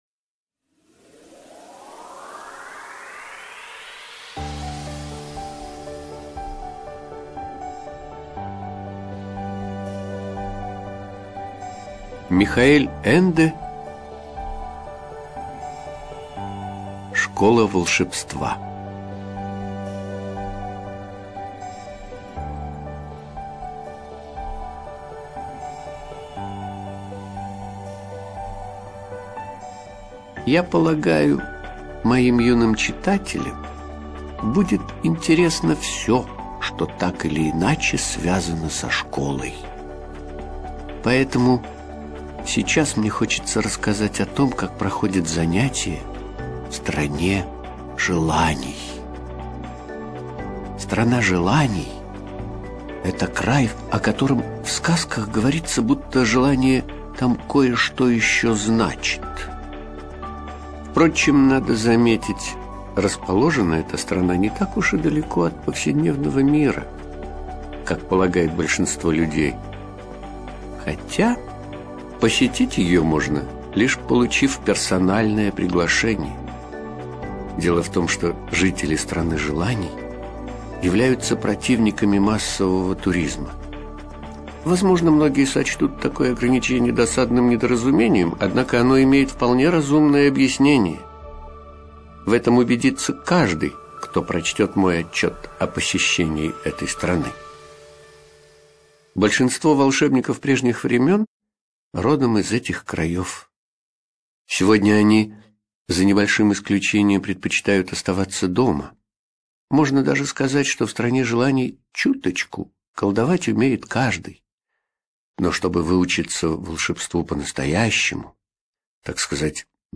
ЖанрСказки
Студия звукозаписиАмфора